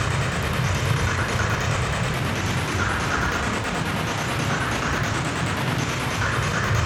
Index of /musicradar/stereo-toolkit-samples/Tempo Loops/140bpm
STK_MovingNoiseE-140_01.wav